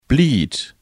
Begriffe von Hochdeutsch auf Platt und umgekehrt übersetzen, plattdeutsche Tonbeispiele, Schreibregeln und Suchfunktionen zu regelmäßigen und unregelmäßigen Verben.